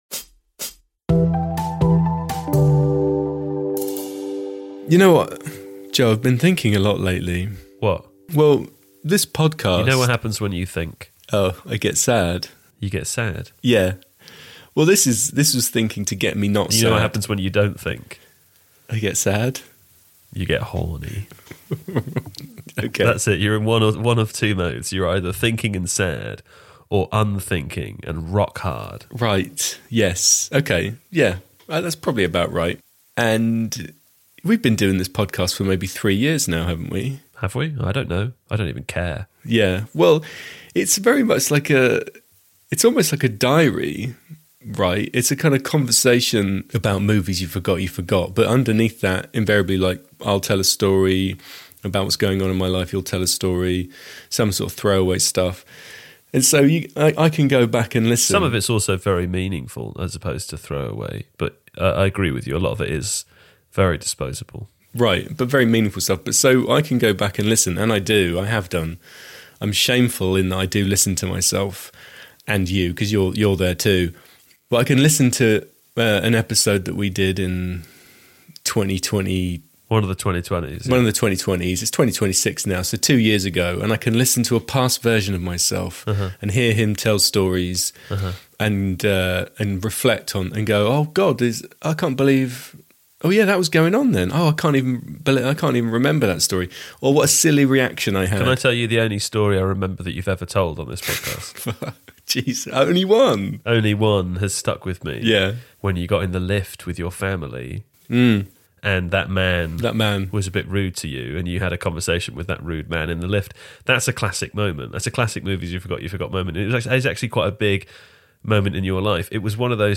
Primer was a darling of Independent cinema in 2004, and holds a place in the hearts of many hard sci-fi fans. This episode includes a discussion about time travel, and a series of impersonations of Hugo Weaving as the Agent from The Matrix.